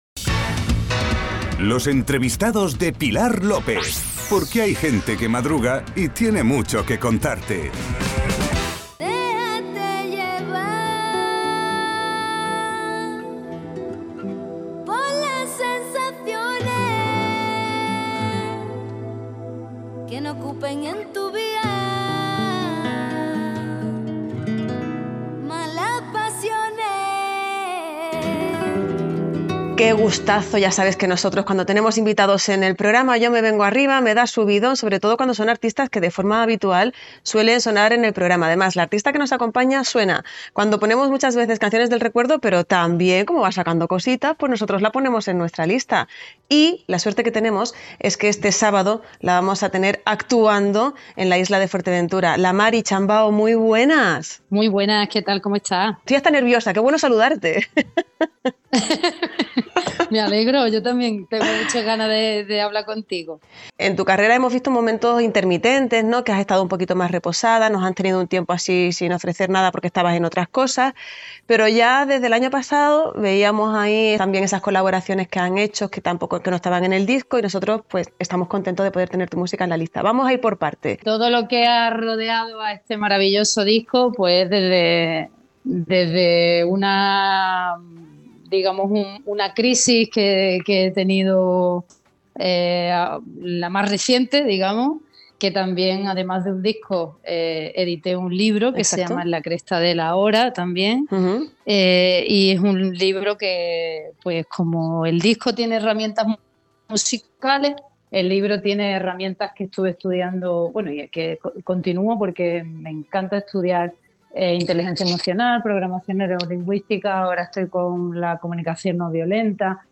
En una charla cercana y vibrante, la artista repasó su trayectoria, habló de su nuevo libro, de cómo ha aprendido a «desnudarse sin vértigo», y anticipó la energía que llevará al Caleta Sound Fest este sábado.
Entrevista-en-Suena-Bien-a-La-Mari-Chambao.mp3